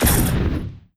Shotgun_Shot.wav